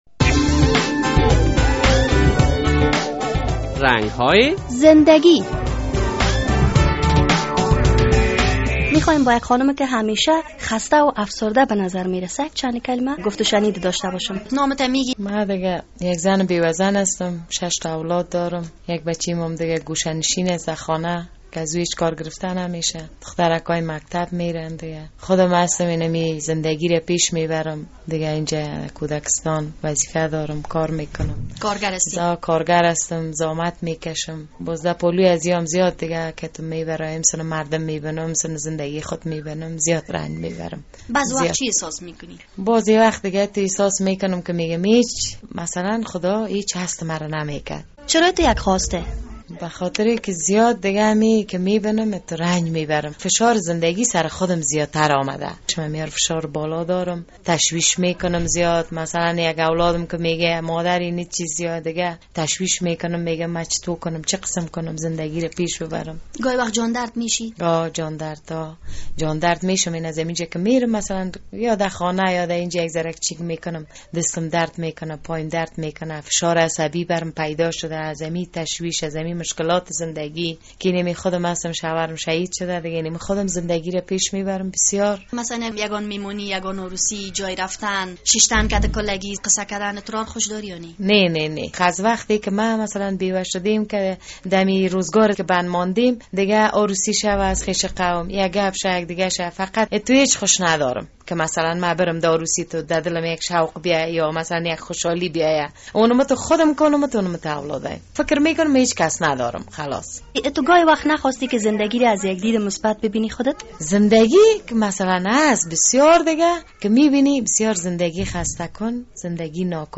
در این برنامهء رنگ های زندگی یک بیوه زن از مشکلات اش می گوید.